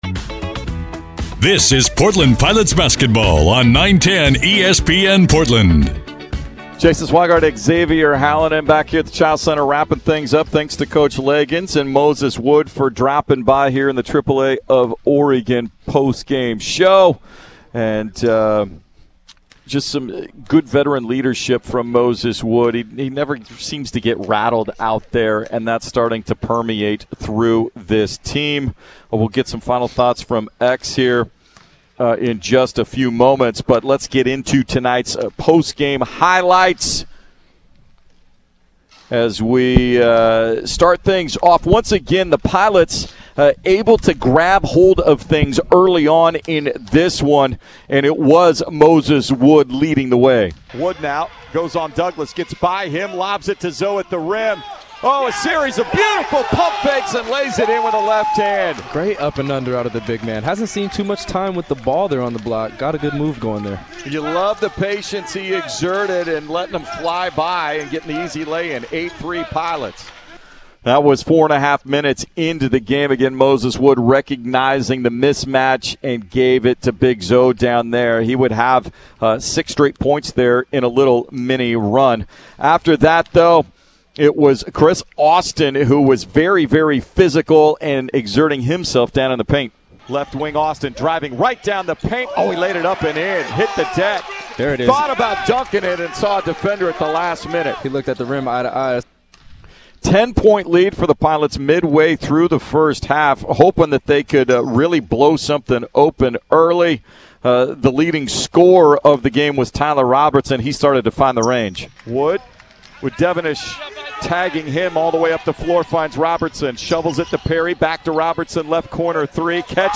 Radio Highlights vs. Morgan State
Highlight_Package.mp3